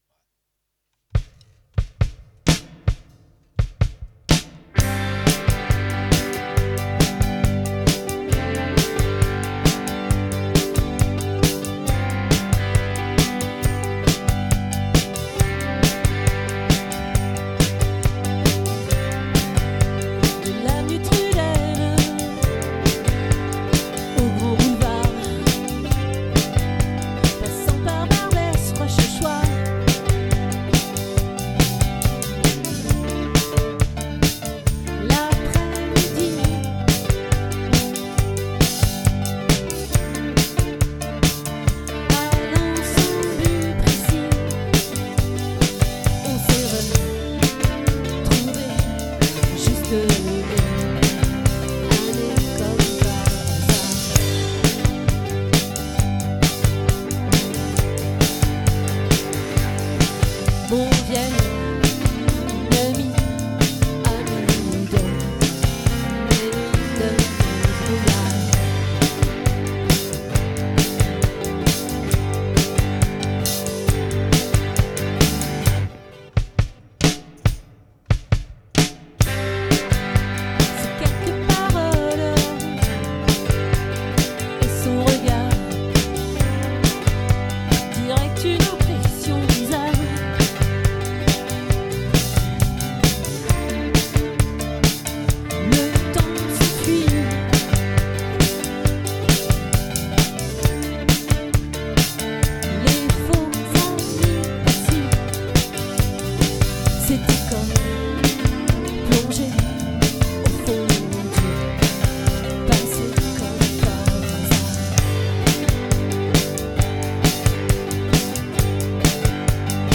🏠 Accueil Repetitions Records_2025_02_17